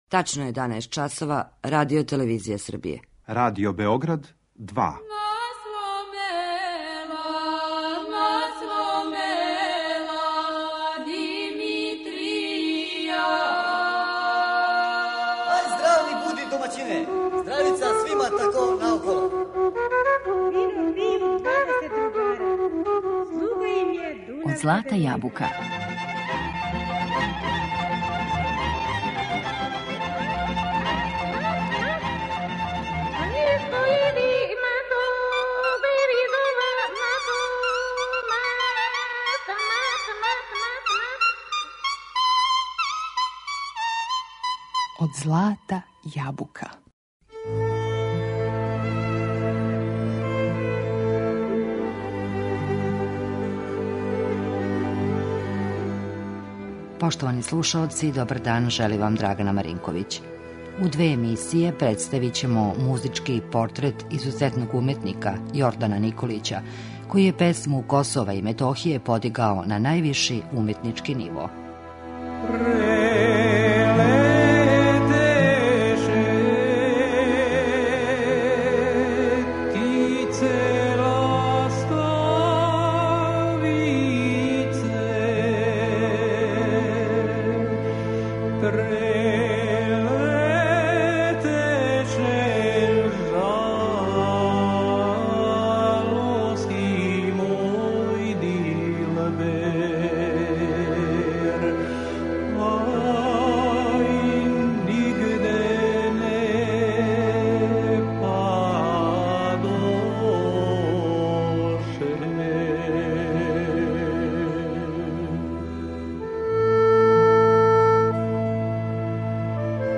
Песме свога краја, поклонио нам је у чистом, изворном стилу, а својом зналачком интерпретацијом, успео је да их оживи.